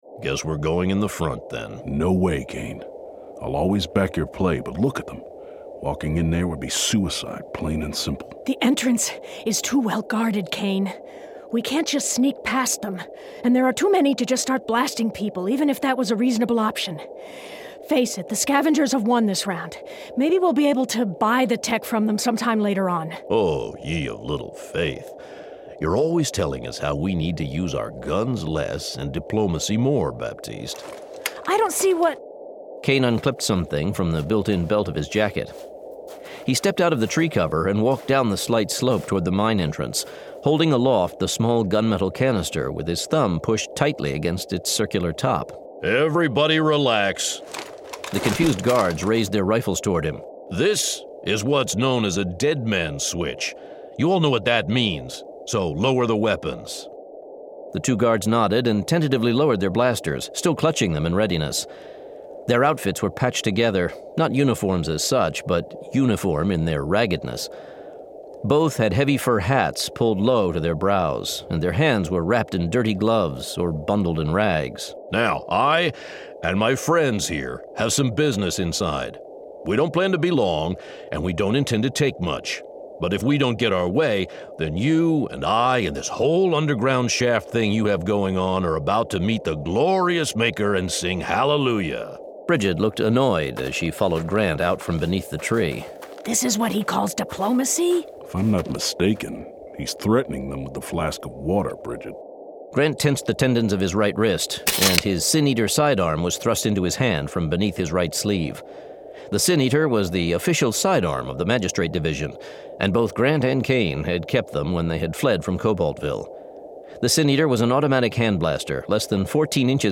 Outlanders 47: Death Cry [Dramatized Adaptation]